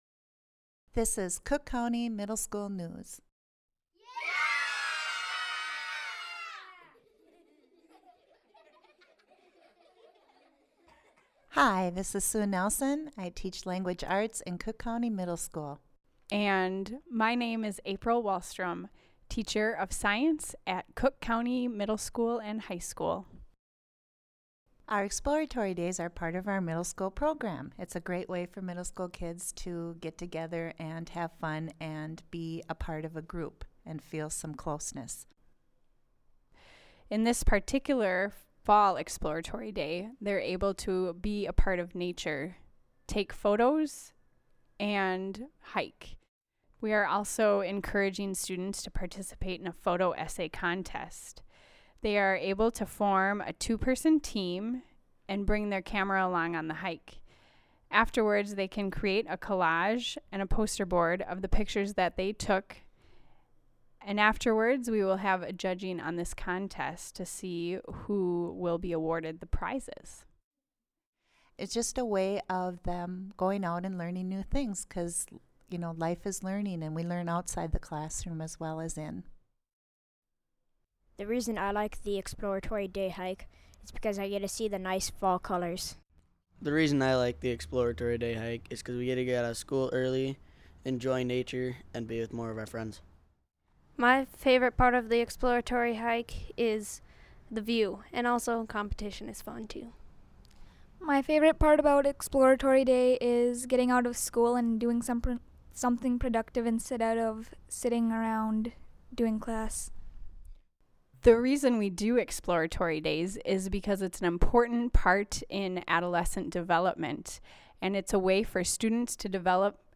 School News